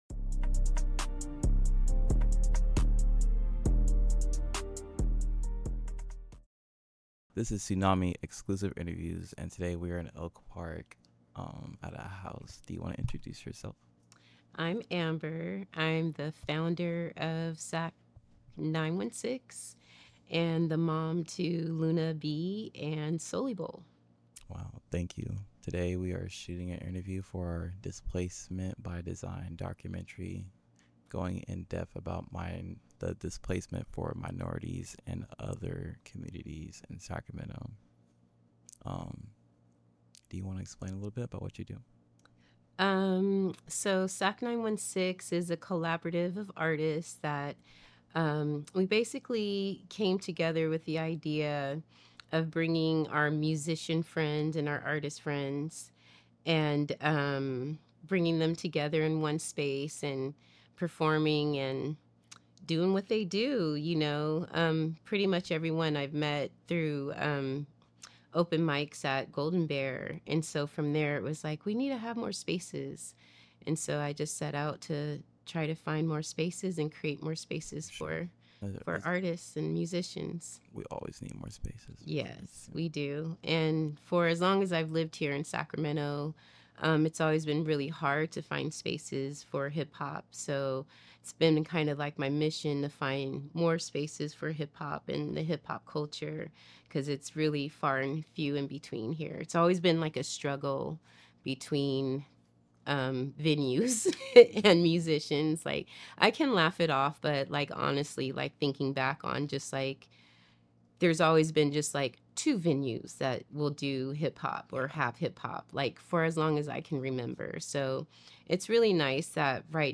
Hosted by Tsunami Media, this show dives deep into authentic conversations with musicians, visual artists, fashion innovators, community leaders, and storytellers shaping the creative and cultural landscape throughout the Golden State.